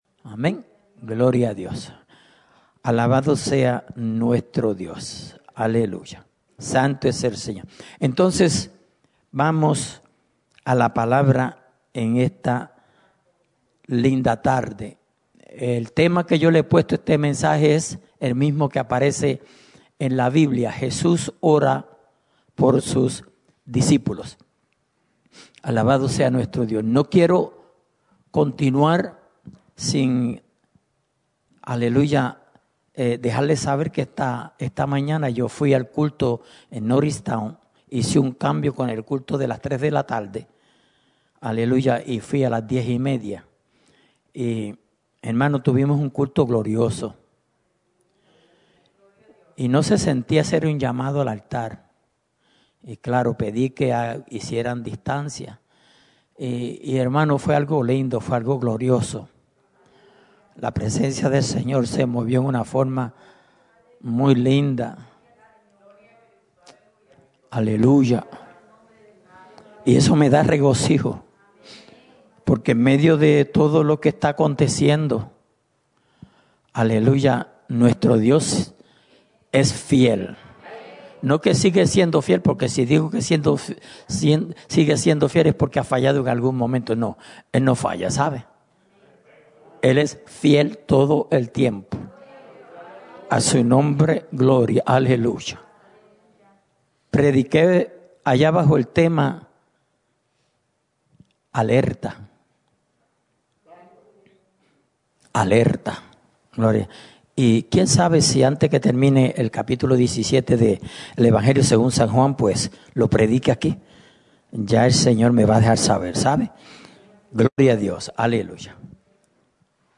Mensaje